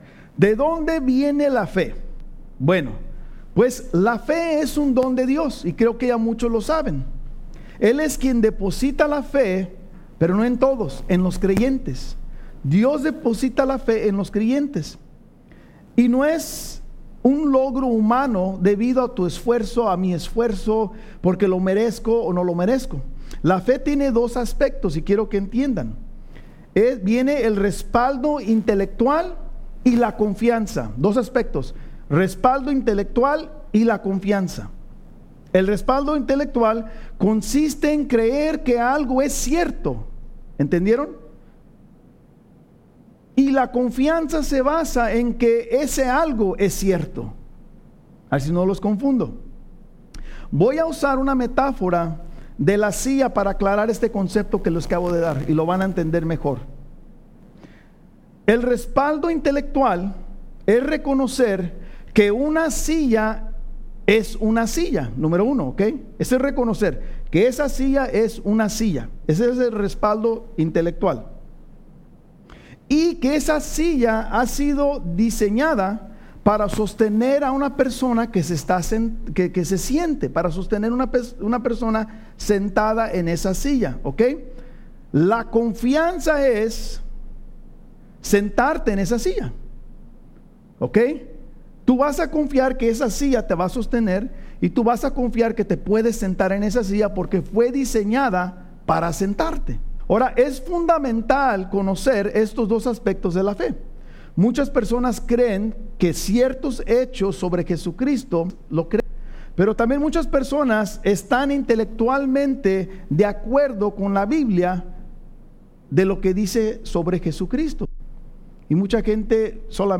Estudio Biblico | Iglesia Vida Hammond